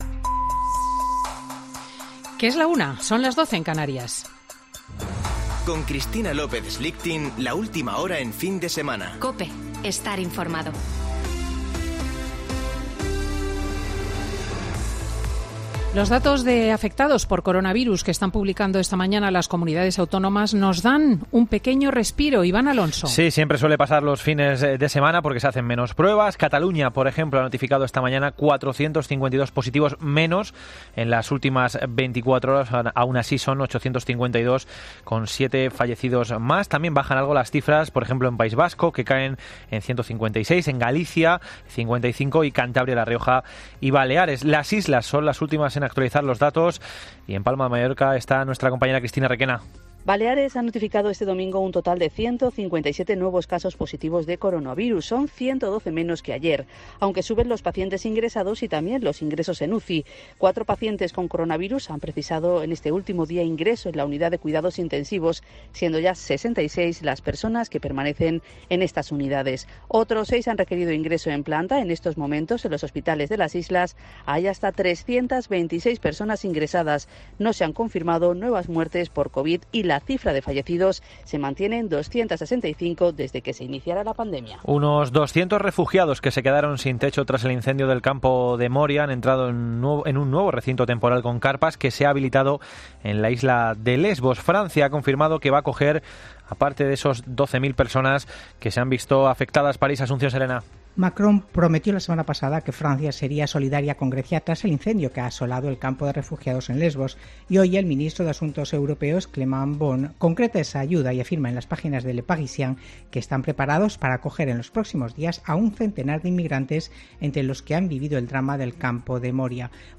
AUDIO: Boletín de noticias de COPE del 13 de septiembre de 2020 a las 13.00 horas